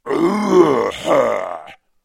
Звуки злодейские
Саркастический смешок (звук)